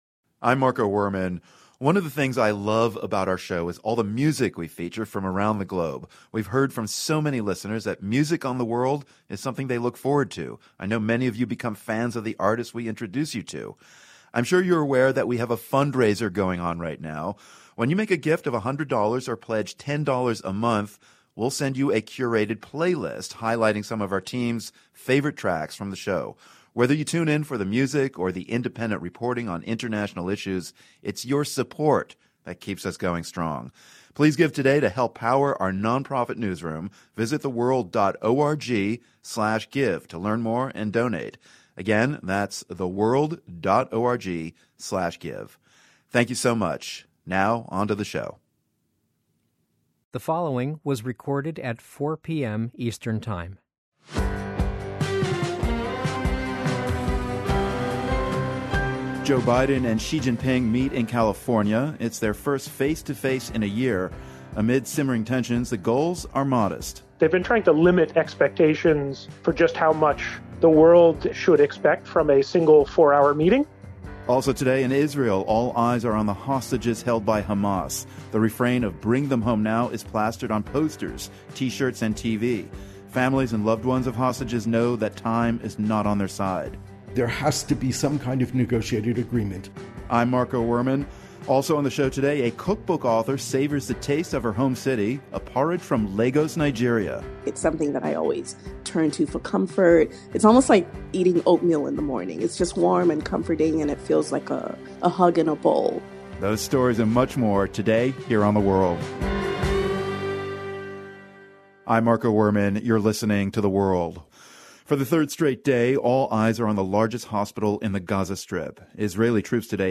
We hear from peace activist Gershon Baskin, who helped negotiate the release of an Israeli soldier from Hamas captivity in 2011.